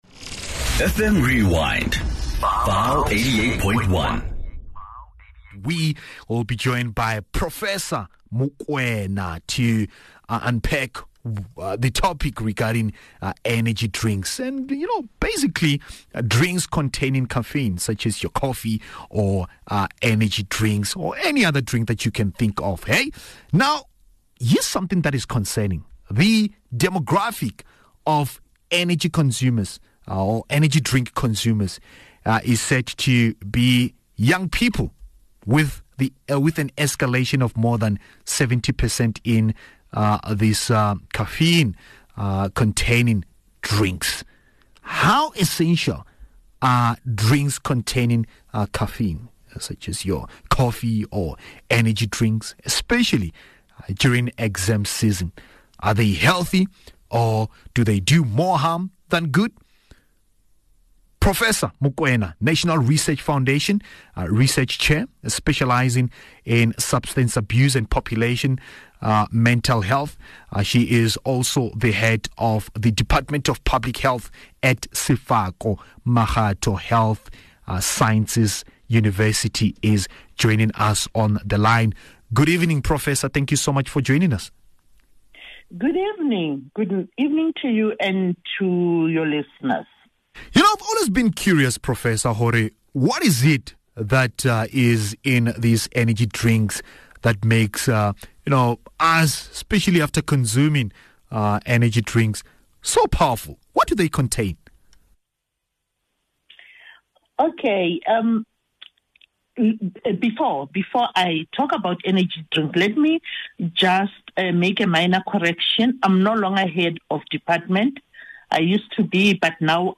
This engaging show explores the latest health and wellness trends, discussing topical issues and breakthroughs in science and technology. Tune in every Thursday from 18:00 to 19:00, as we bring you expert insights, interviews, and discussions on various health topics, from local concerns to global perspectives.